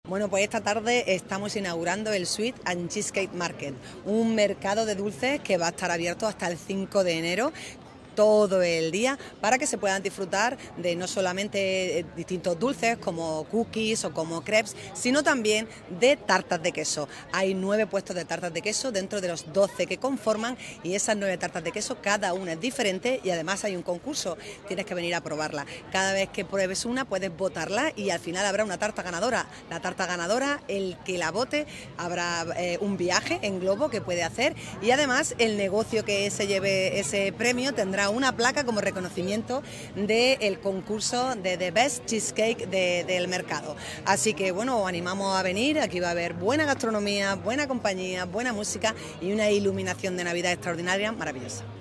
ALCALDESA-CHEESECAKE-MARKET.mp3